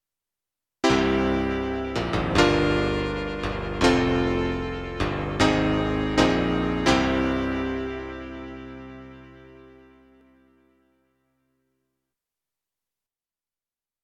Identité sonore